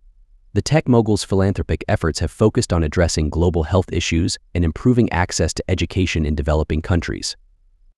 Play.ht-The-tech-moguls-philanthropic-efforts.wav